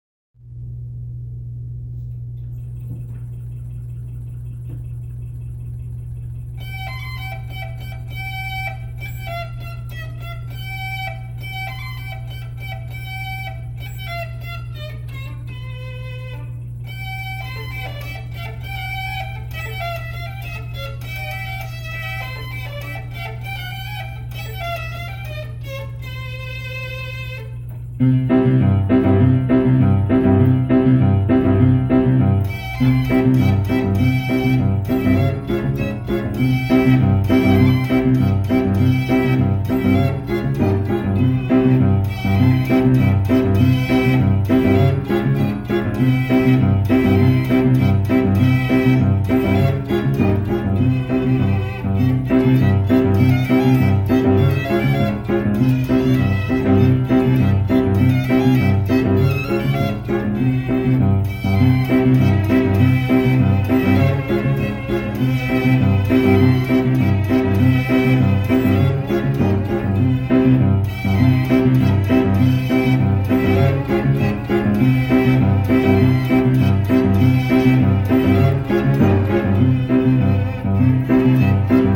This instrument uses spinning discs of rosin to play the violin strings, each driven separately.
Mills-Double-Violano.mp3